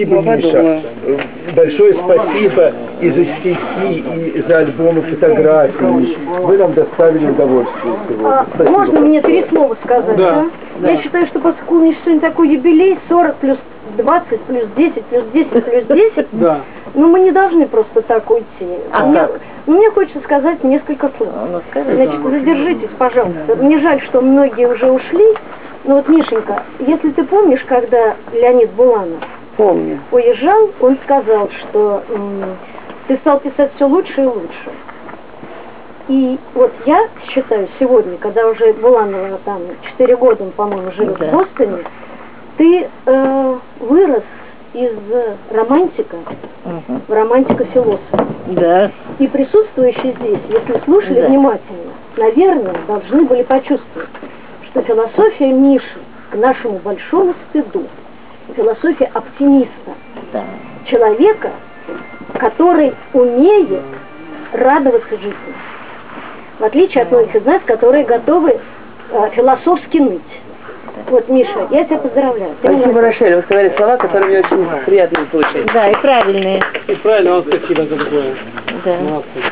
Отзыв слушателя (аудио)